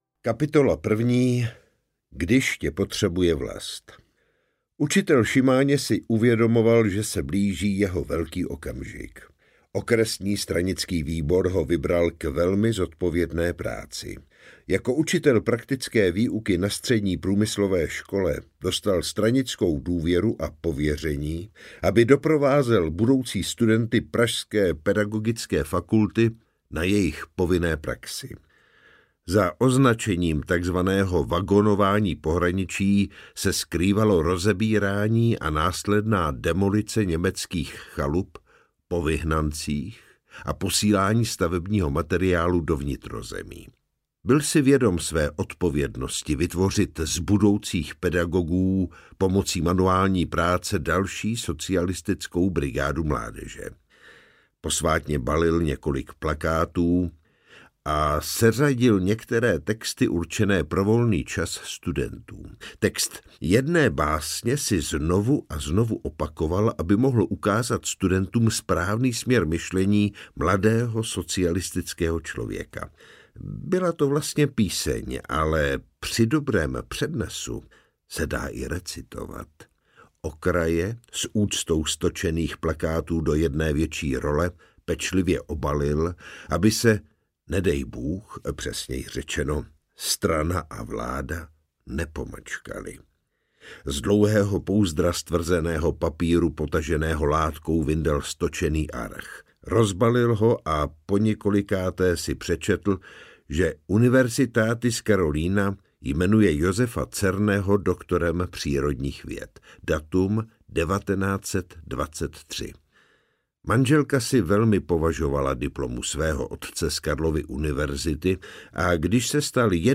Interpret:  Jiří Šesták
AudioKniha ke stažení, 24 x mp3, délka 7 hod. 5 min., velikost 388,1 MB, česky